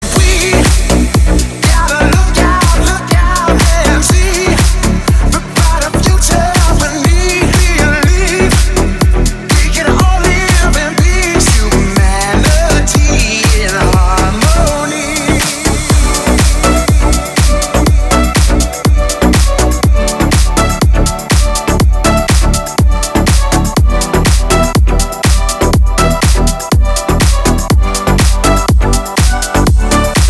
• Качество: 320, Stereo
громкие
Стиль: deep house